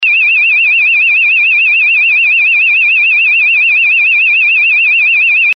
Serie: SIRENAS DE GRAN POTENCIA ACÚSTICA - SIRENAS DIRECCIONALES
45 Sonidos seleccionables - 141dB